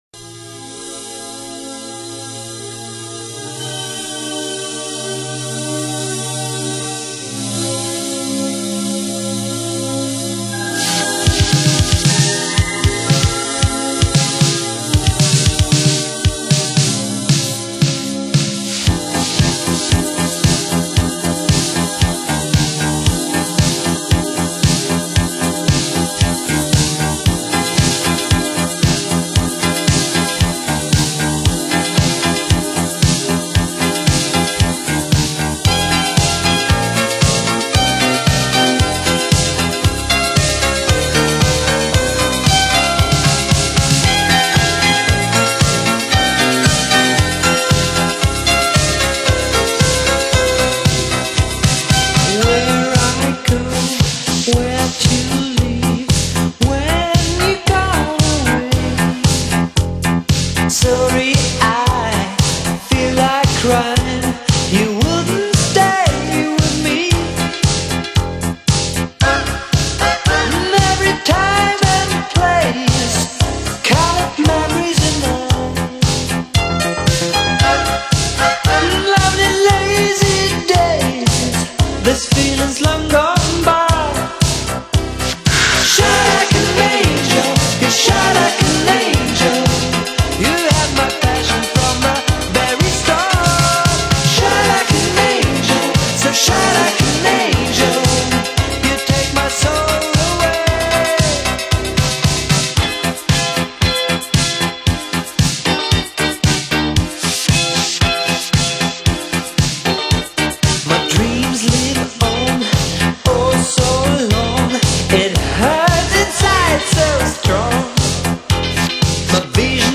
旋律感都很强，都是属于韵律悠扬的电子音乐